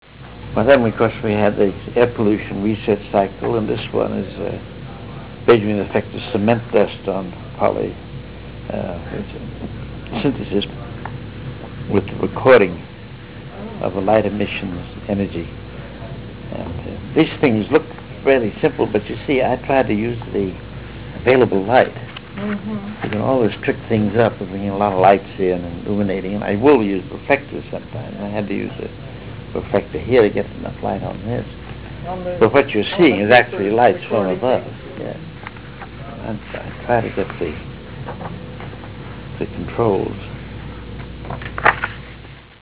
342Kb Ulaw Soundfile Hear Ansel Adams discuss this photo: [342Kb Ulaw Soundfile]